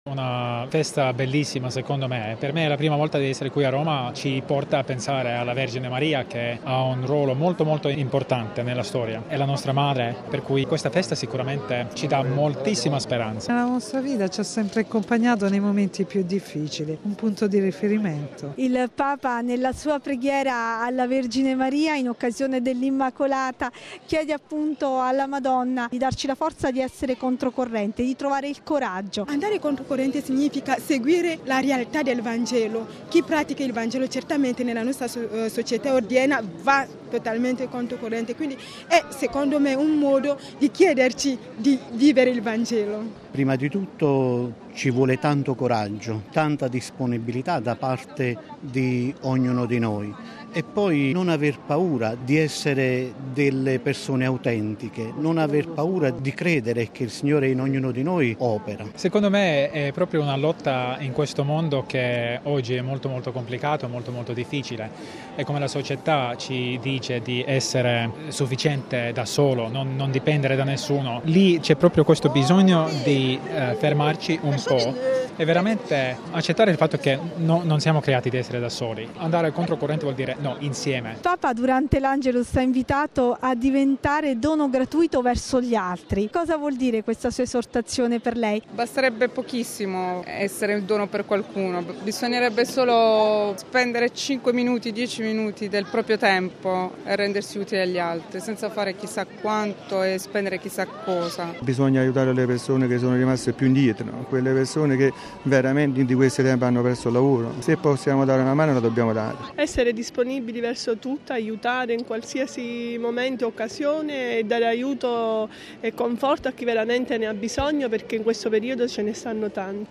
Decine di migliaia dunque i fedeli che hanno gioiosamente invaso Piazza di Spagna per poter incontrare Papa Francesco in visita alla statua dell'Immacolata.